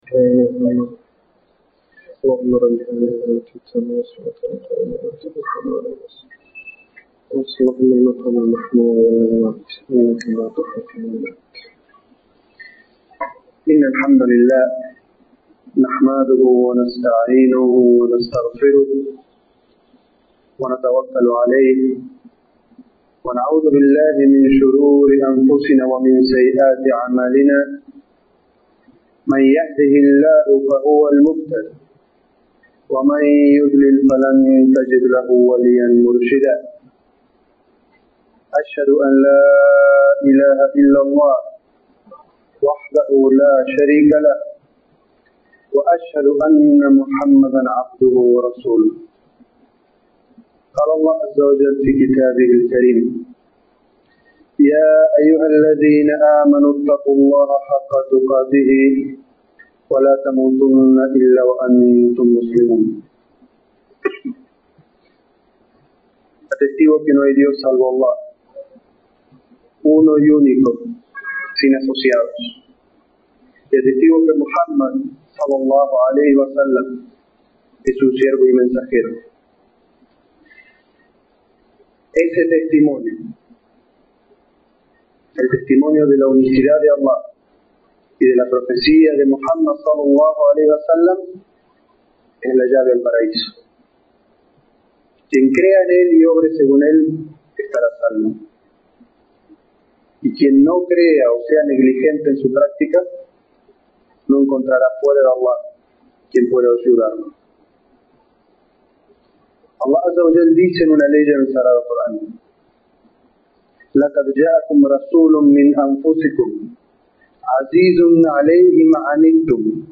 Jutbah sobre la vida de Muhammad, que la paz y las bendiciones de Allah sean con él, la obligación
es-jutbah-nuestro-profet-muhammad.mp3